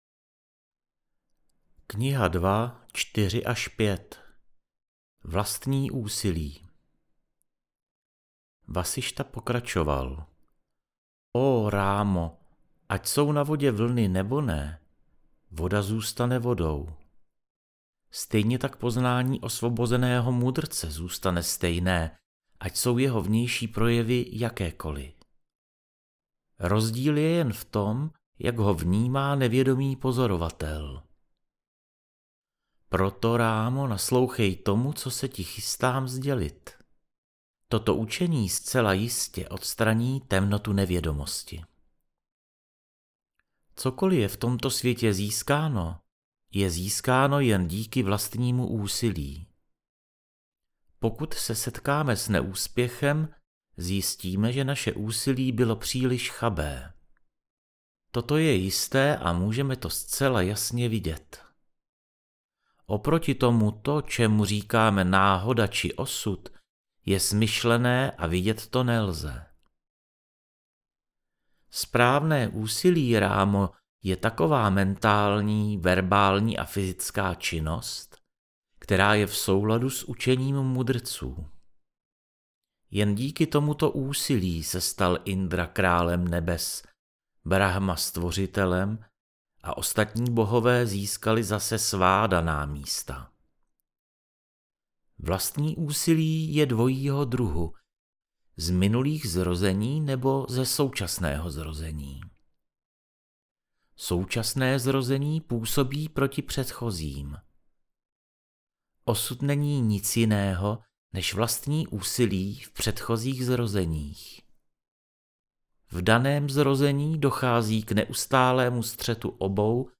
JÓGA VÁSIŠTHA - AUDIOKNIHA